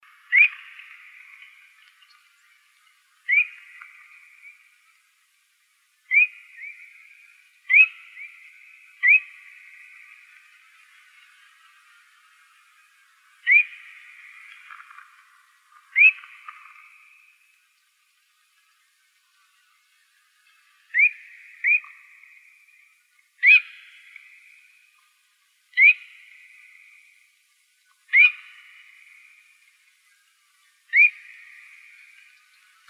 Phainopepla.wav